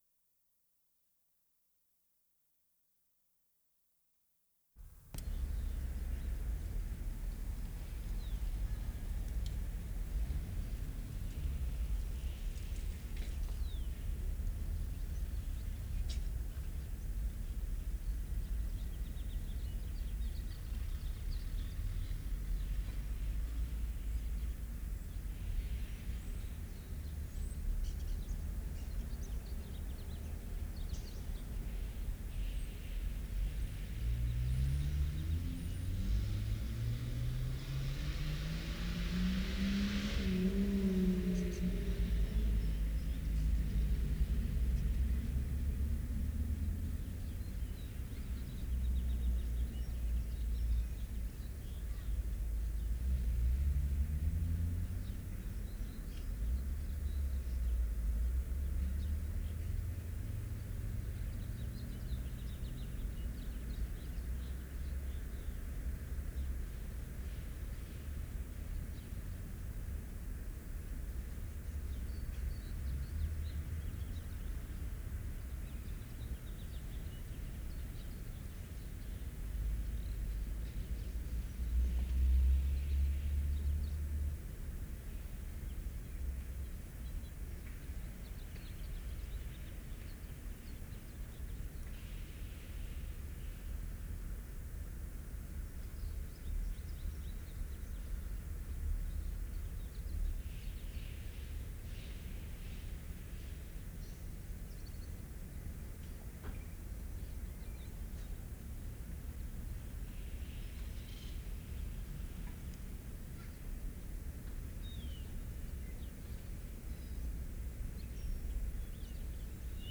5. Different location, birds chirping, traffic events are discrete, some work-type sounds in the background.
2'55" car passes, followed by distant train whistle.
4'35" short train toot and single echo.
5'00" - people, voices and footsteps, passing by.